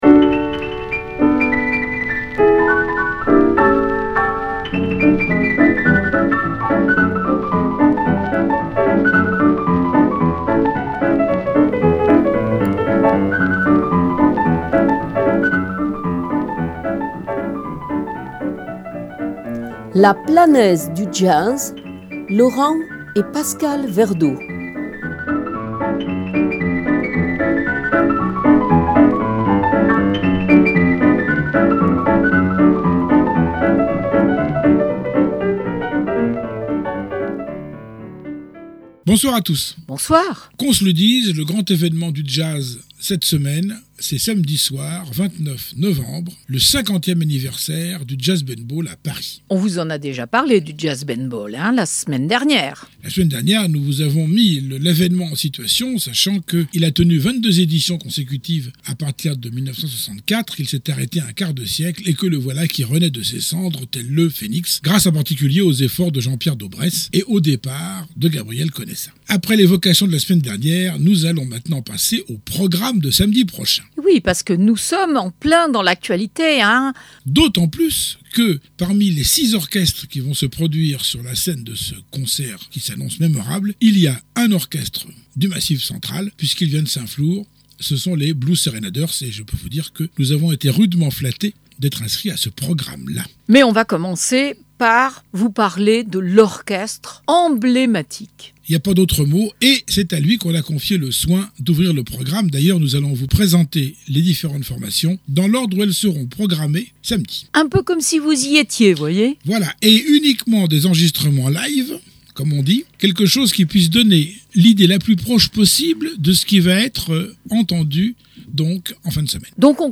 Emission sur le Jazz